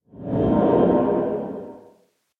Minecraft Version Minecraft Version latest Latest Release | Latest Snapshot latest / assets / minecraft / sounds / ambient / cave / cave12.ogg Compare With Compare With Latest Release | Latest Snapshot
cave12.ogg